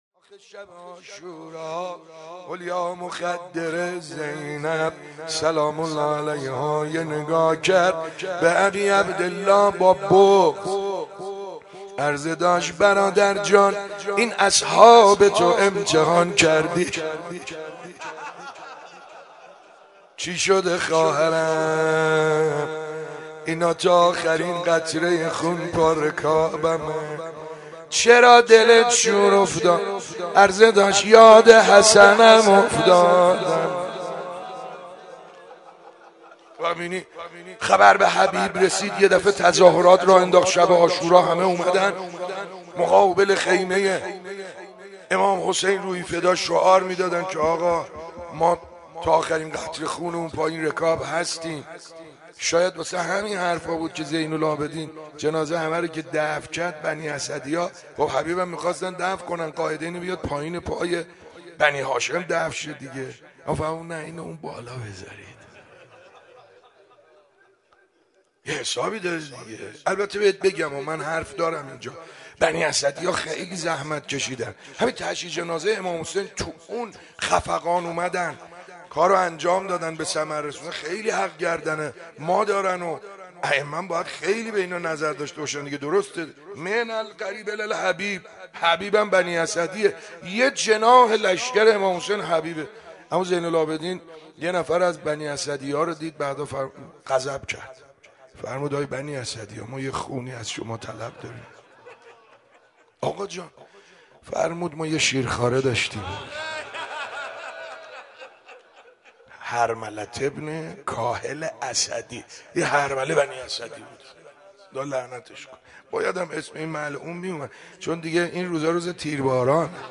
صبح شهادت امام مجتبی(ع) مهدیه امام حسن(ع)
مدح خوانی
روضه
سینه زنی